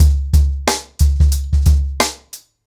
TrackBack-90BPM.67.wav